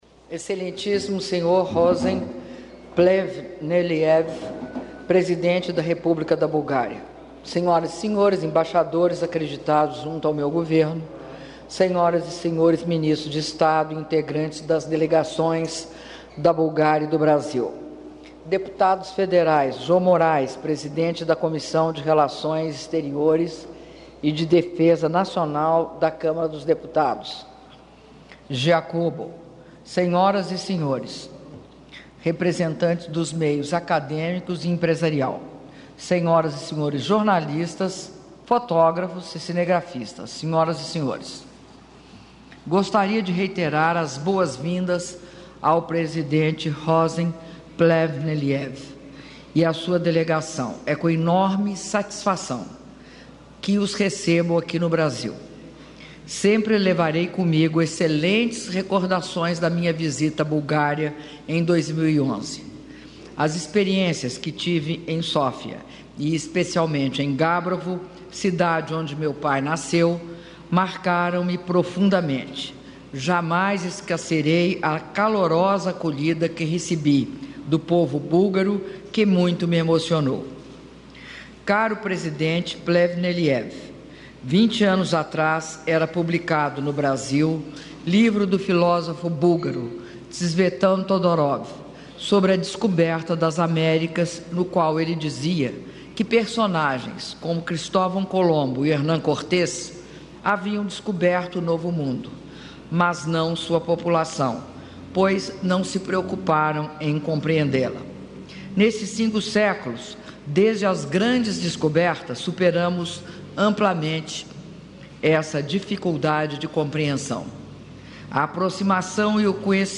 Áudio do brinde da Presidenta da República, Dilma Rousseff, durante almoço em homenagem ao presidente da Bulgária, Rosen Plevneliev - Brasília/DF (05min16s)